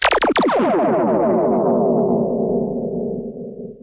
00168_Sound_laser.mp3